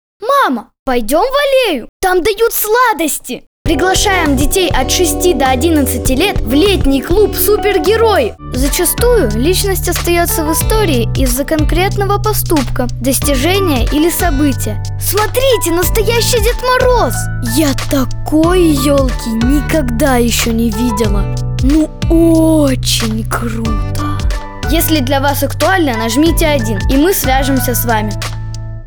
Детский голос онлайн.
Скачать демо диктора